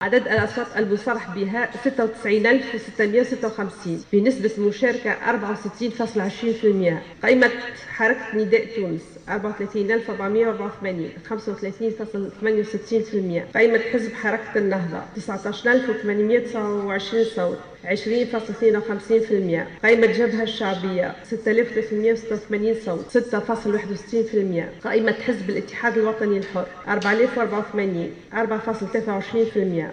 Lamia Zargouni, membre de l’ISIE a annoncé lors de la conférence de presse tenue aujourd’hui les rsultats progressifs des élections législatives dans la circonscription de Jendouba :